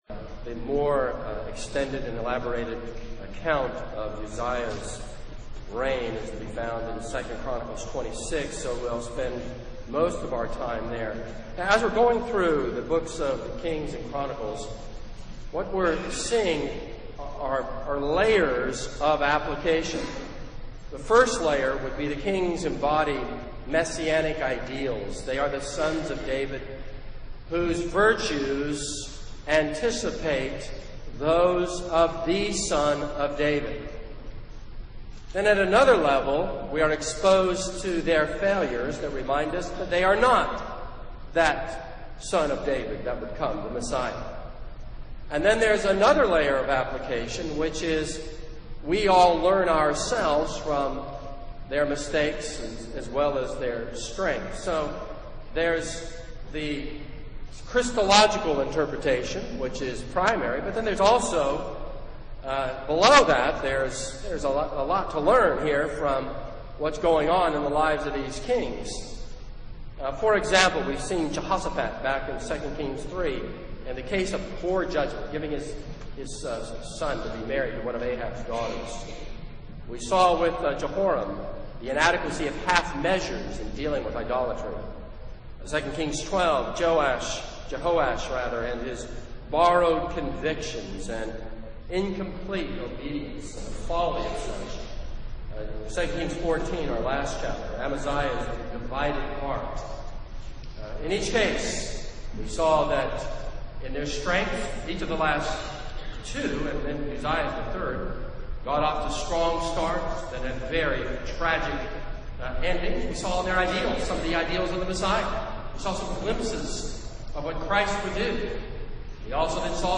This is a sermon on 2 Kings 15:1-7.